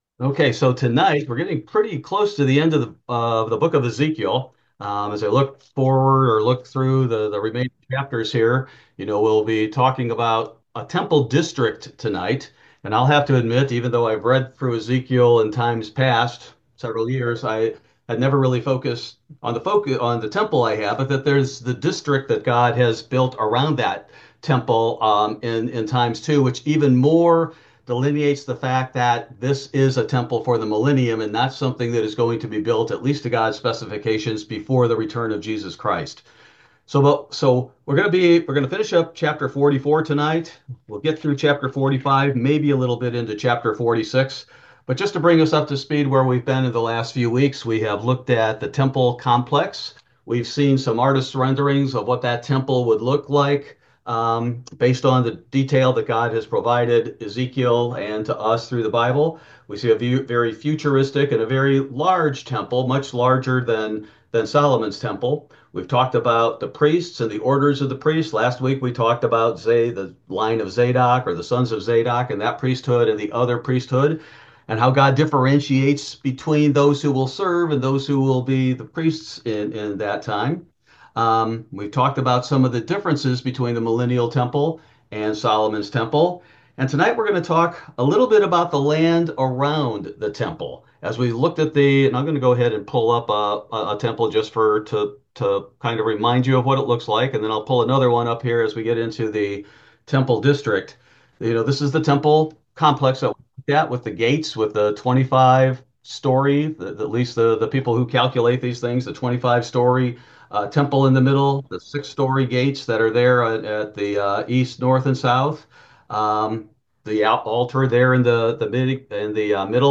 Ezekiel Bible Study: April 9, 2025